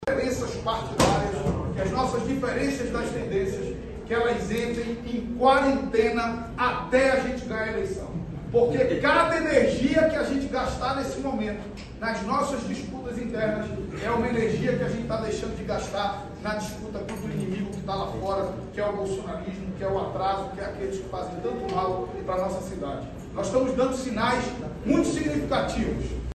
No ato que confirmou seu nome, Marcelo pediu união em torno de um projeto progressista em Manaus e que as tendências do PT buscassem esquecer as divergências durante o processo eleitoral: